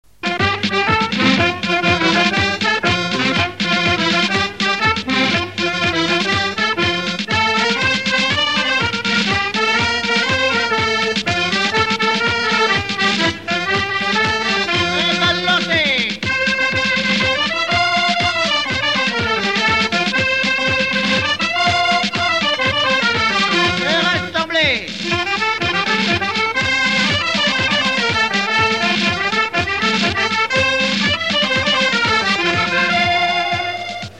Avant deux
Résumé instrumental
Pièce musicale inédite